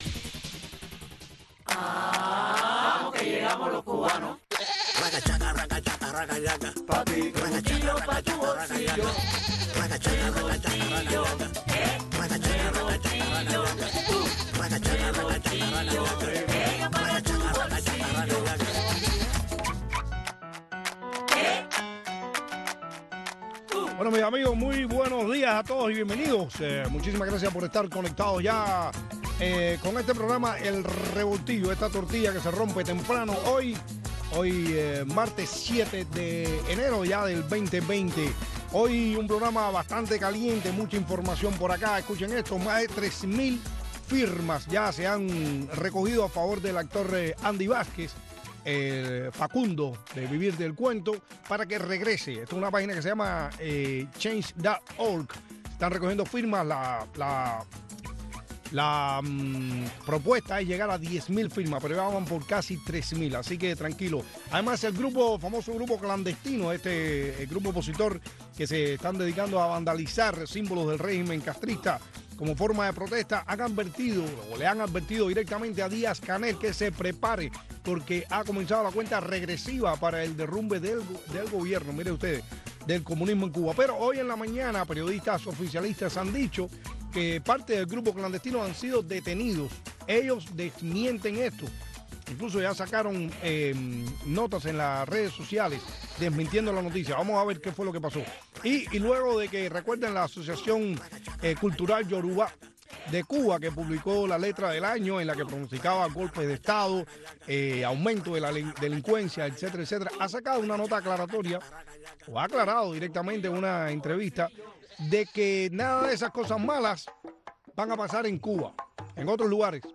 comparte entrevistas, anécdotas y simpáticas ocurrencias.